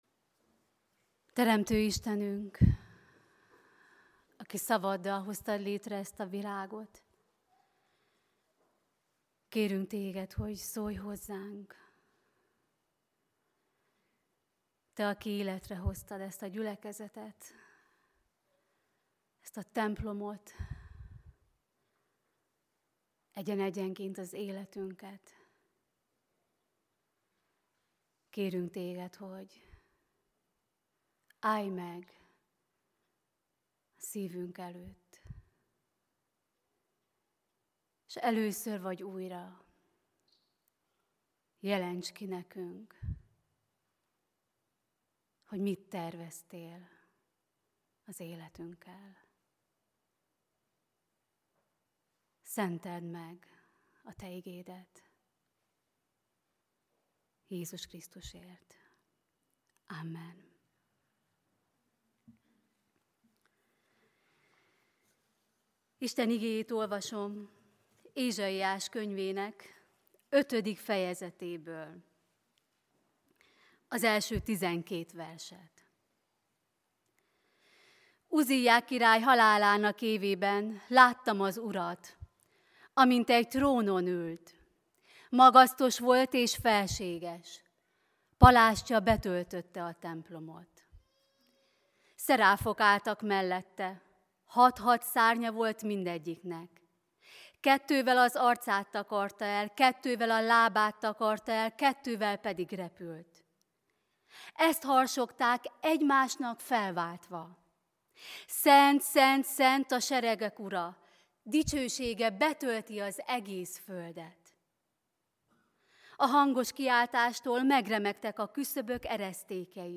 AZ IGEHIRDETÉS LETÖLTÉSE PDF FÁJLKÉNT AZ IGEHIRDETÉS MEGHALLGATÁSA
Lekció: Ézs 6,1-13/Textus: Ézs 6,8 2026. január 1. - dicsőítő istentisztelet